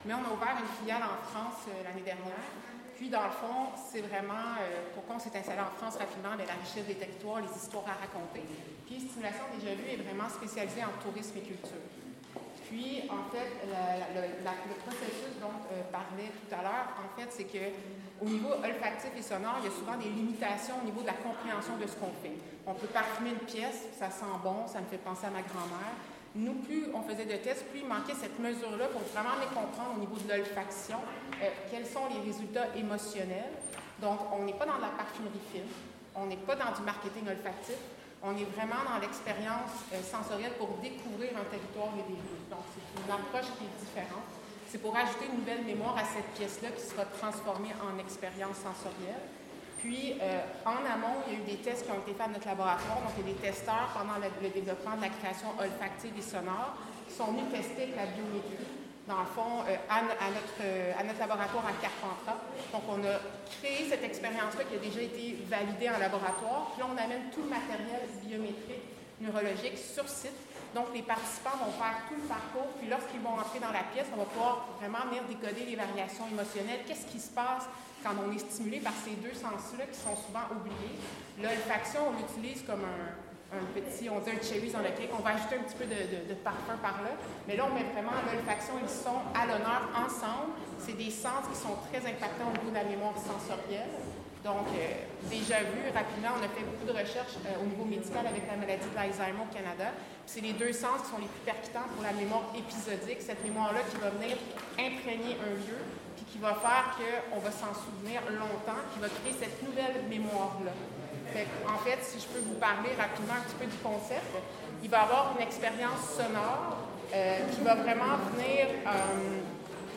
au micro, de Fréquence Mistral Avignon